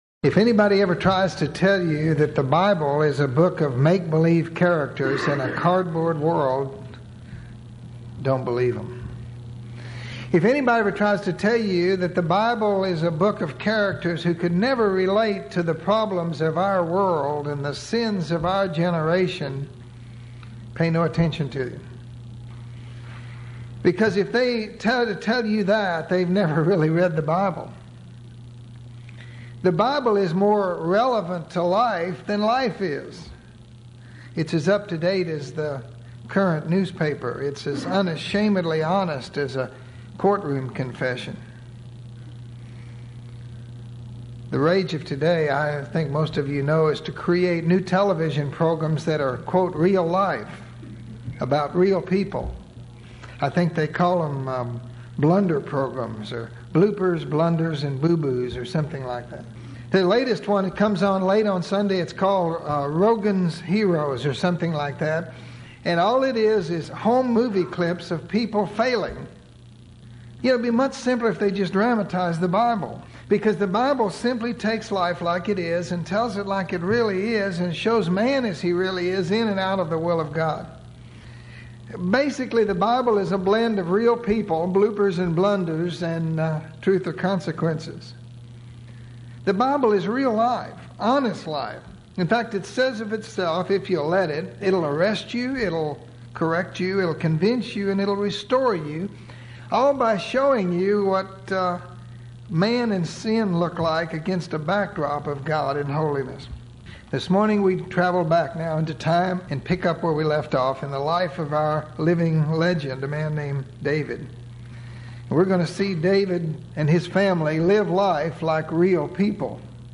This lesson takes a look at one of those consequences that broke the heart of David, the rebellion of his son Absalom.